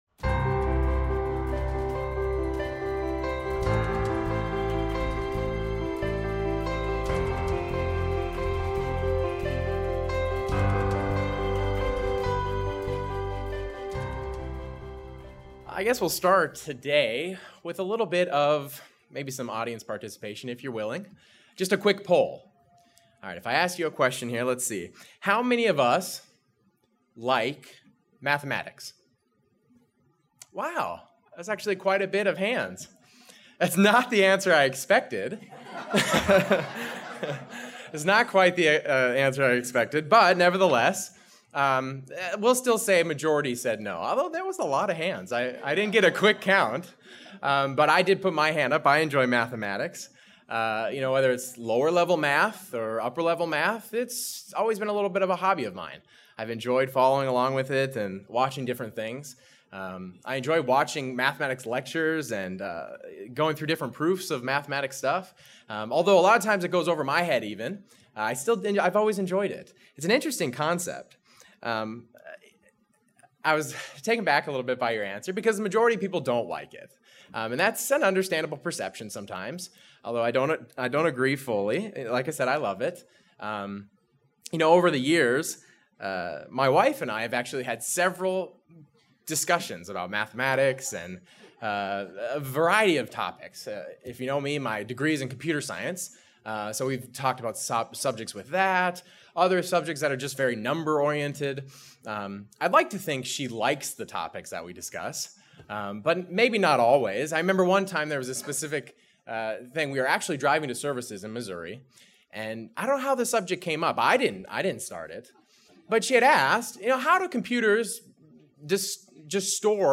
Counting has a unique application in God's Word. This message examines the importance of counting the Feast of Pentecost.